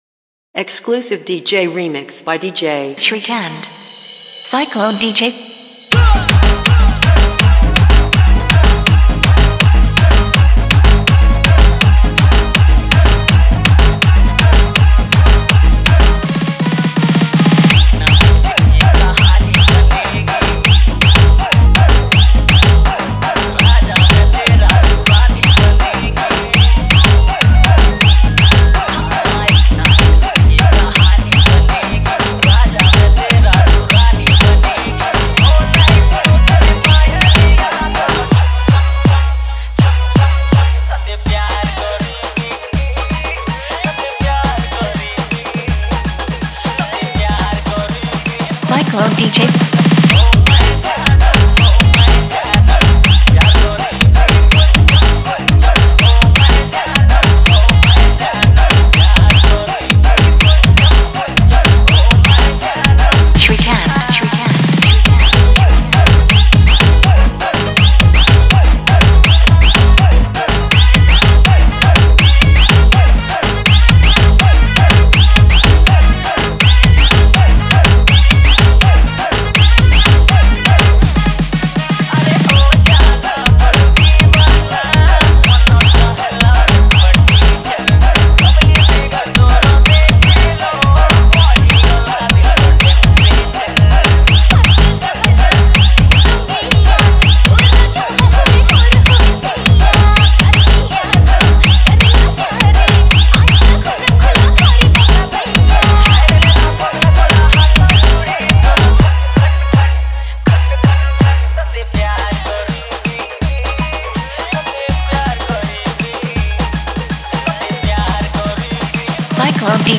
Odia Dance Dj Song